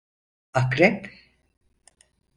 olarak telaffuz edilir [ɑkɾep]